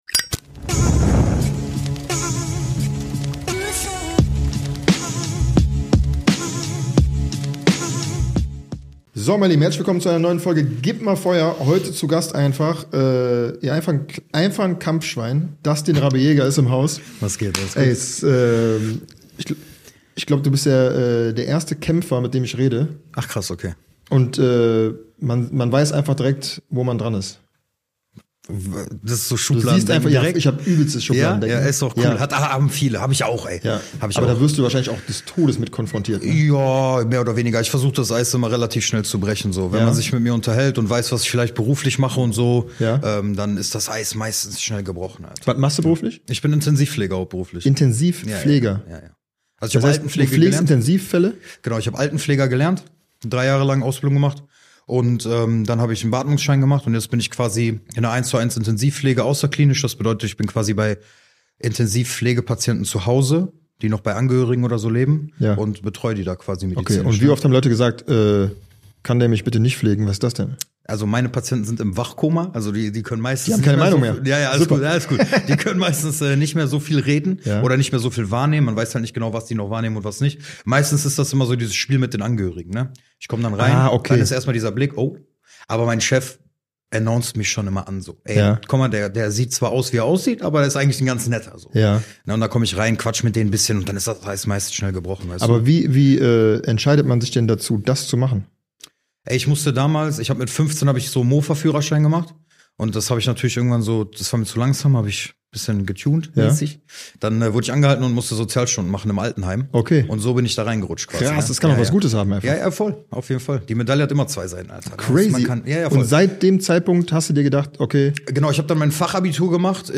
Bei „Gib ma Feuer!“ dürfen seine Gäste, bestehend aus charismatischen Prominenten oder Menschen mit spannenden und ungewöhnlichen Berufen, ihre mitreißenden Geschichten und besonderen Sichtweisen teilen. Gleichzeitig fordert er sie mit ungewöhnlichen Fragen heraus, die natürlich besondere Antworten erfordern.